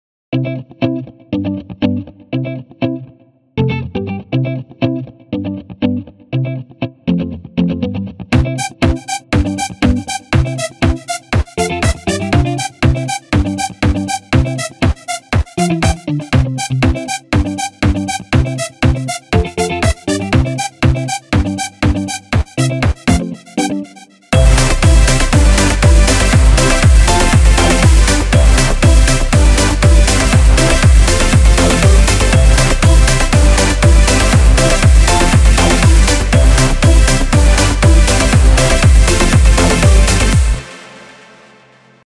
Ich finde dieses Nexus-Zeug hört sich immer sehr nah am Mic an ...